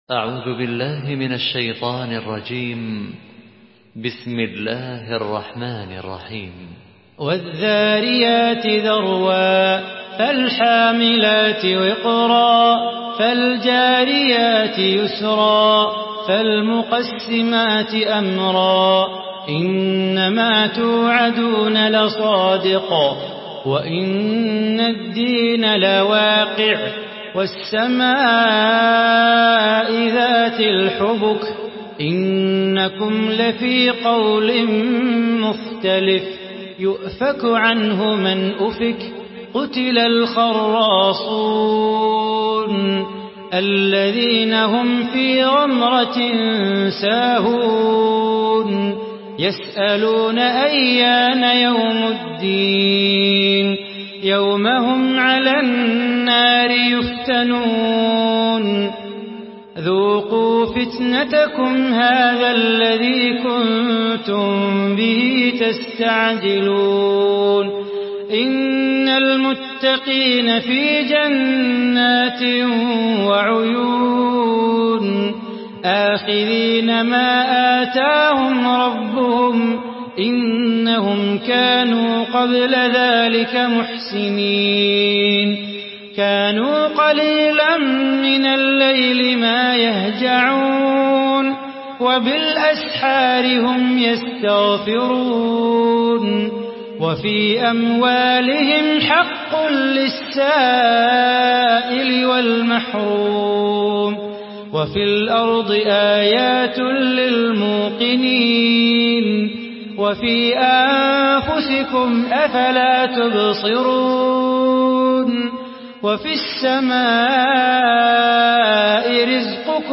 Surah Ad-Dariyat MP3 in the Voice of Salah Bukhatir in Hafs Narration
Murattal